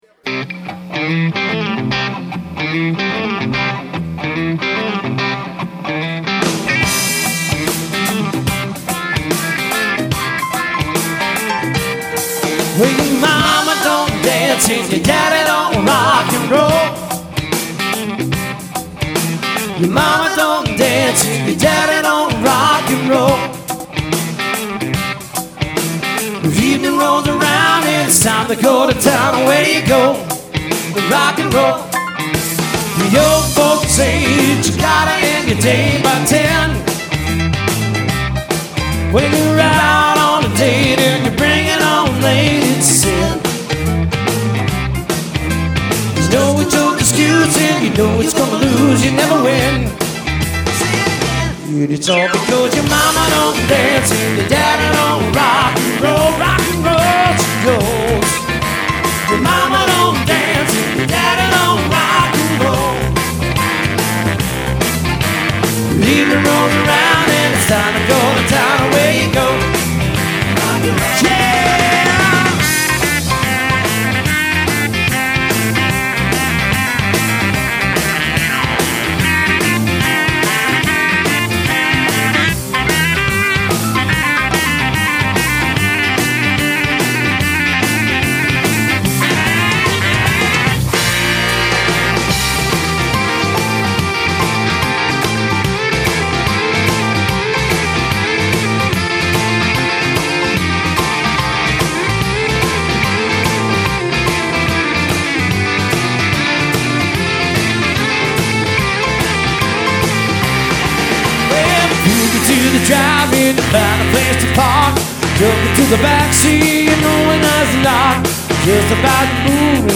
Acoustic/electric guitars and one singer.
(guitar, vocals with backing tracks)